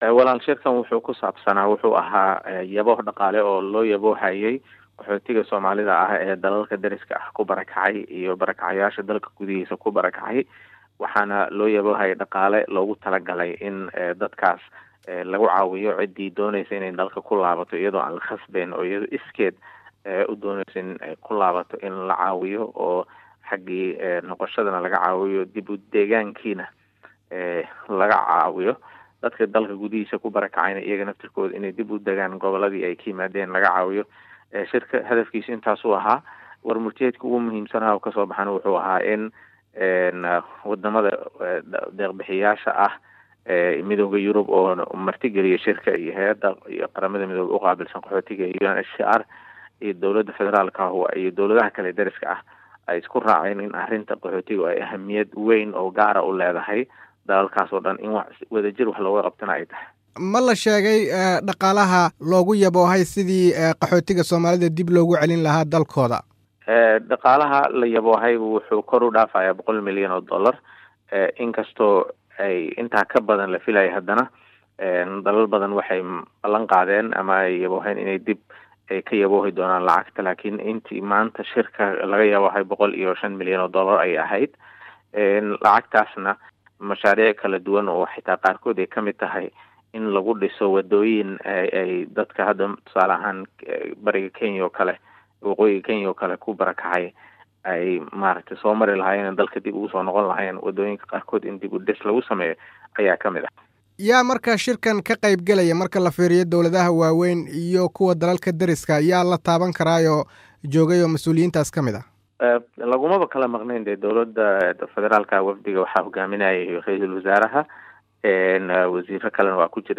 Wareysi: Cabdiraxmaan Ceynte